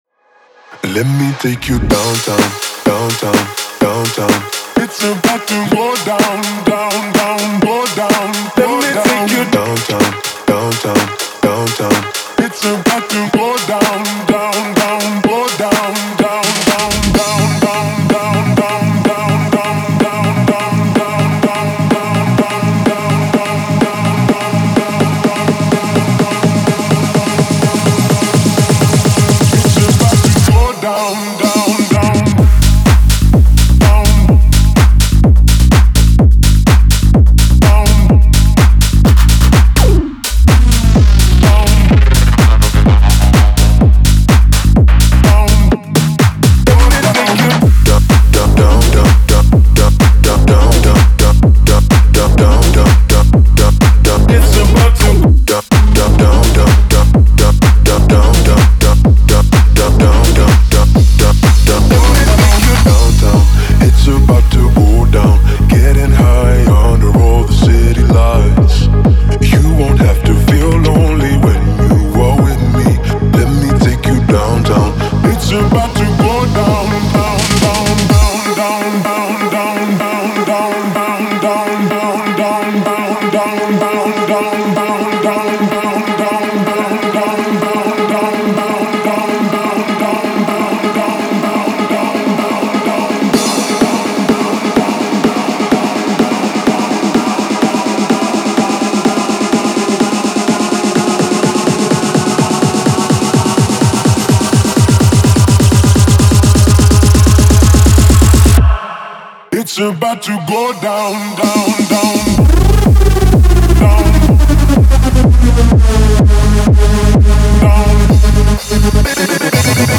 это энергичный трек в жанре EDM